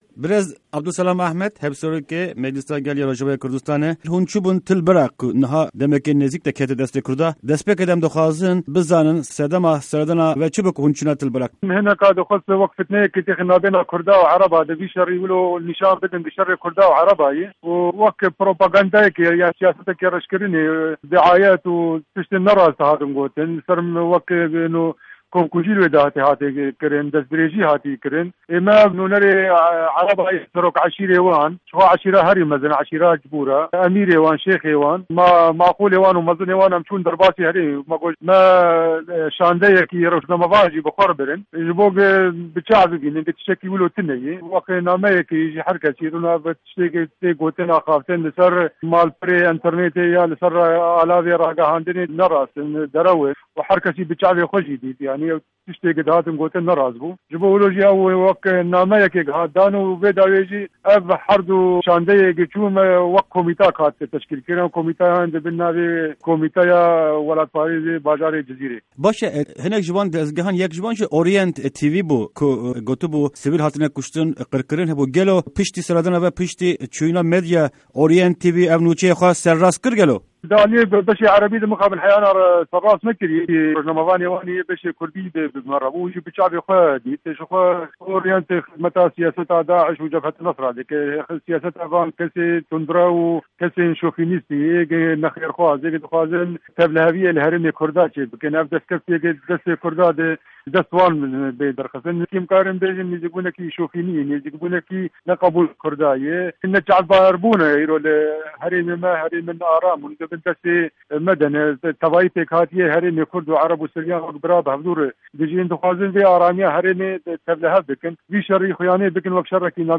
Di hevpeyvîna Dengê Amerîka de Abdulselam Ahmed, Hevserokê Meclîsa Gel ya Rojavayê Kurdistanê (MGRK) li ser wê serdanê dixife û çavdêrîyên xwe ligel me parve dike.